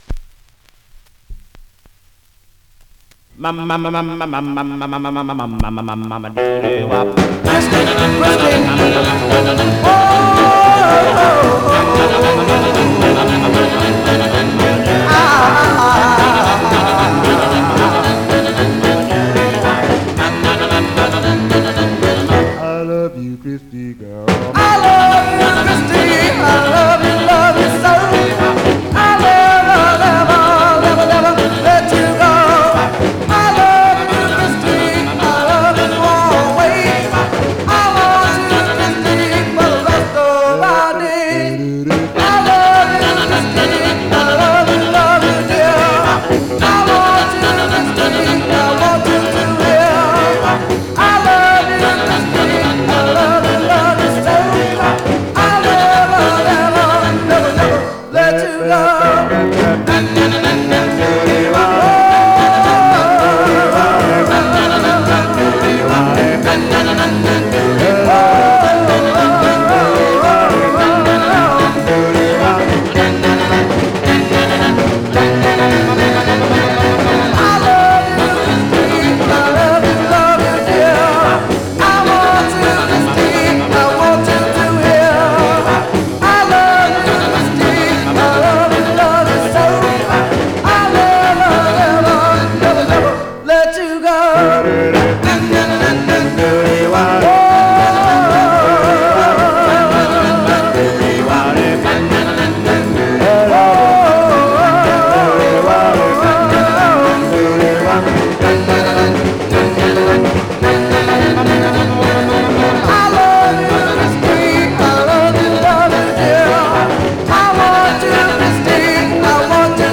Some surface noise/wear
Mono
Male Black Group Condition